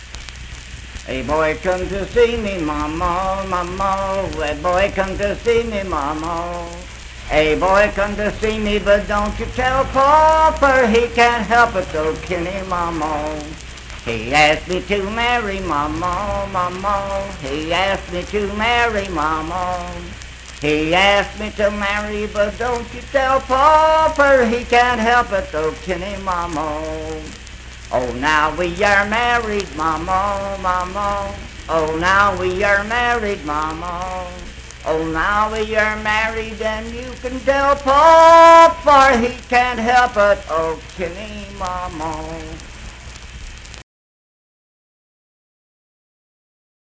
Unaccompanied vocal music performance
Dance, Game, and Party Songs
Voice (sung)